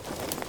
tac_gear_39.ogg